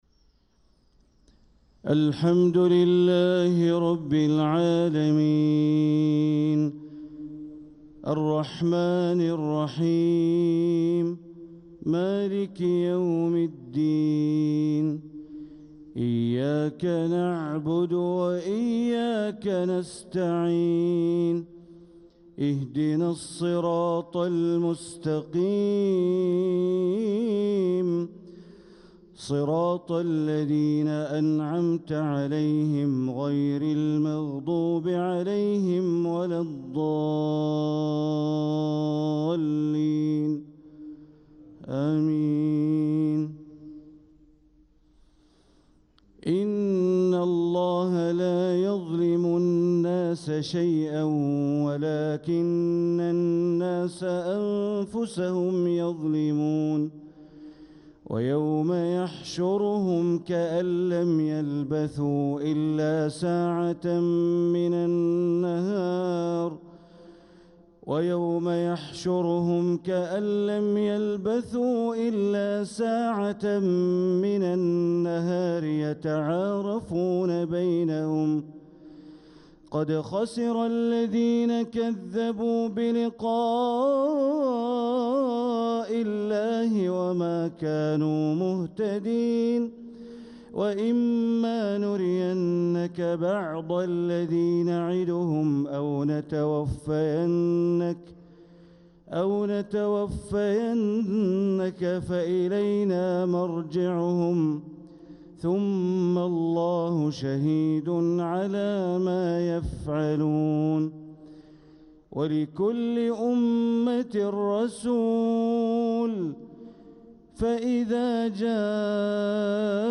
صلاة الفجر للقارئ بندر بليلة 20 ربيع الآخر 1446 هـ
تِلَاوَات الْحَرَمَيْن .